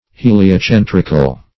\He`li*o*cen"tric"al\ (h[=e]`l[i^]*[-o]*s[e^]n"tr[i^]*kal), a.